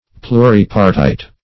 Search Result for " pluripartite" : The Collaborative International Dictionary of English v.0.48: Pluripartite \Plu`ri*par"tite\, a. [Pluri- + partite.]
pluripartite.mp3